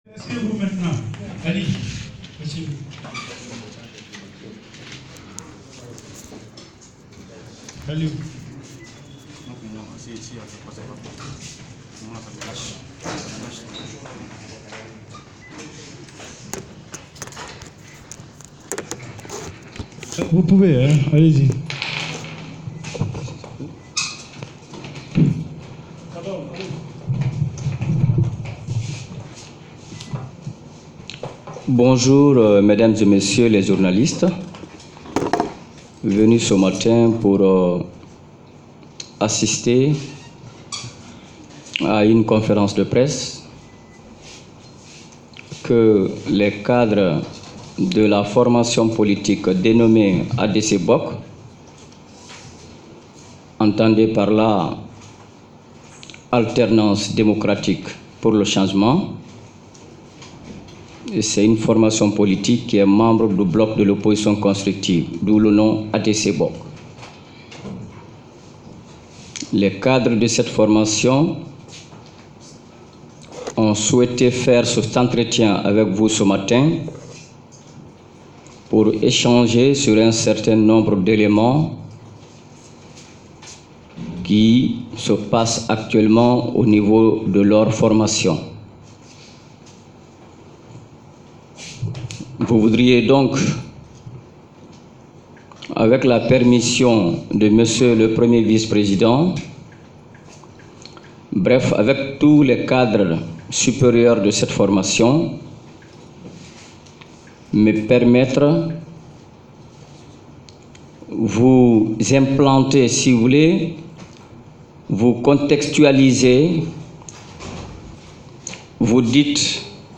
Dans une déclaration rendue publique ce jeudi 1er avril 2021, à la maison de la maison des journalistes sis à kipé, une vingtaine cadres du parti Alternance démocratique pour le changement du Bloc de l’opposition constructive (ADC-BOC) ont annoncé leur démission. Ces responsables du parti ont dénoncé des dysfonctionnements et la gestion unilatérale par l’honorable Ibrahima Sory Diallo.(extrait)
Voici ci-dessous l’intégralité audio de ladite déclaration